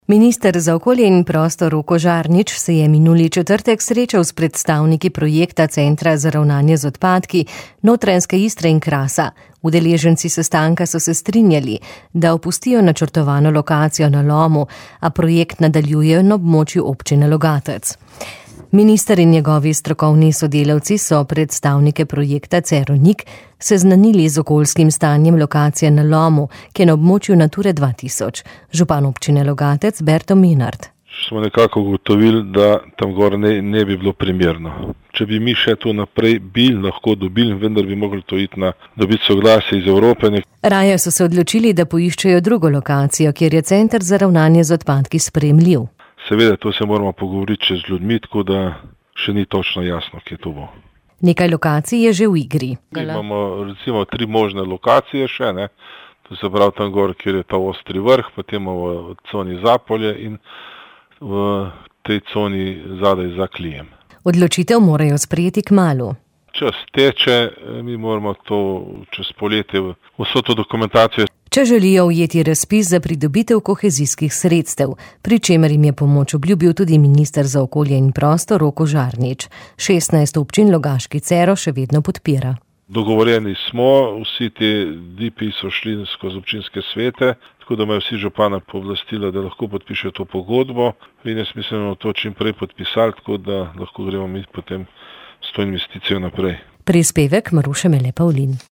Logaški župan Berto Menard pravi, da bodo občani izbirali med Ostrim Vrhom, Zapoljem in lokacijo za Klijem.